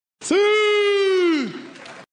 Siuuuu Meme Sound Effect sound effects free download